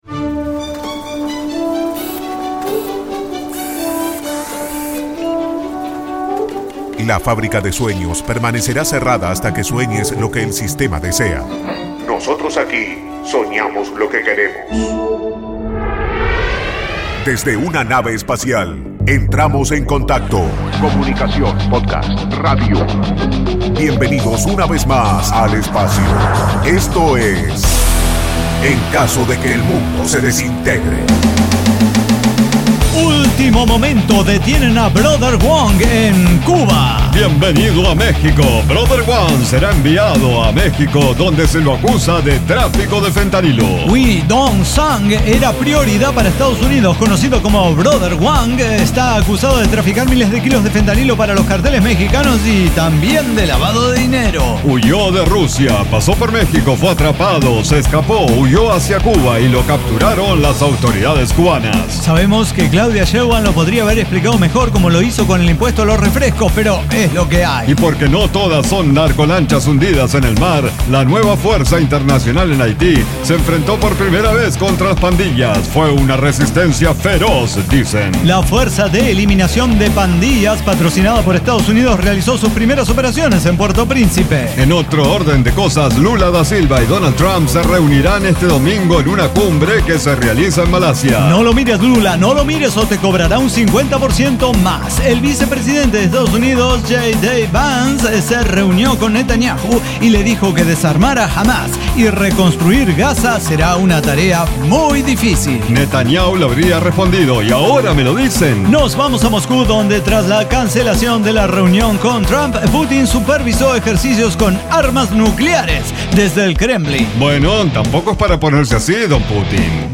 NO AI: ECDQEMSD Podcast no utiliza ninguna inteligencia artificial de manera directa para su realización. Diseño, guionado, música, edición y voces son de nuestra completa intervención humana.